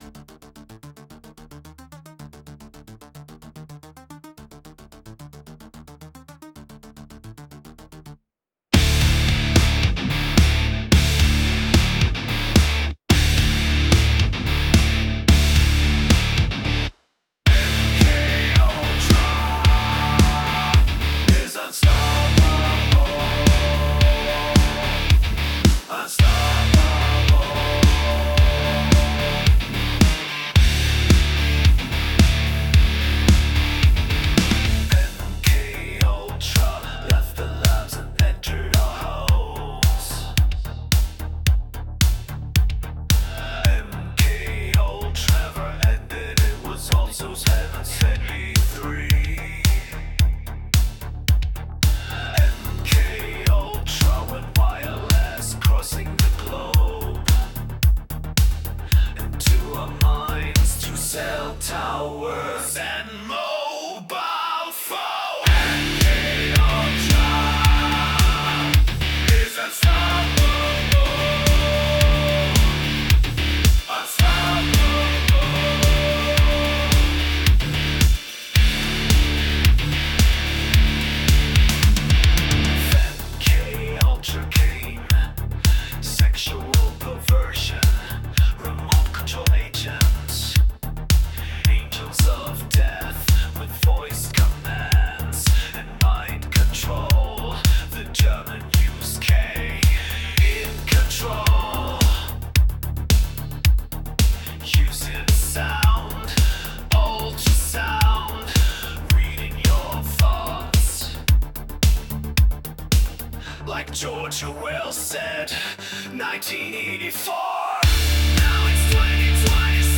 HEAVY METAL VERSION LYRICS